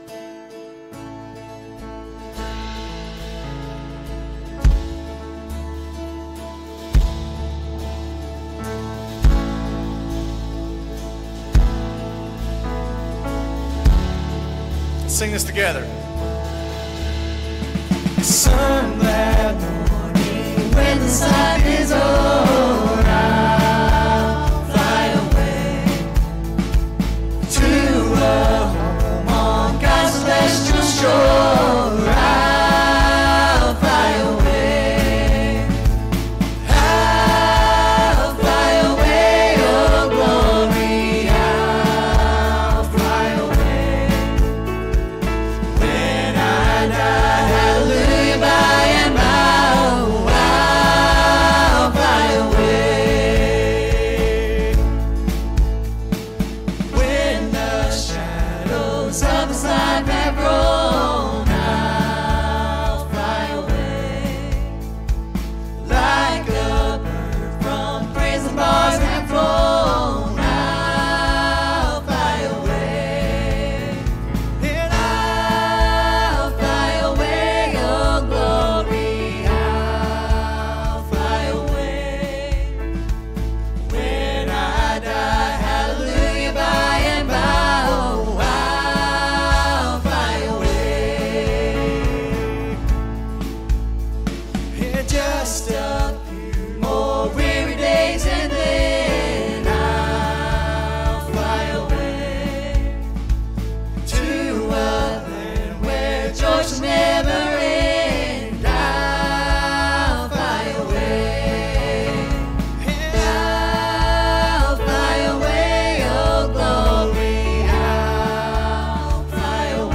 Sunday morning sermon on The Silent Sins Hindering the Great Commission.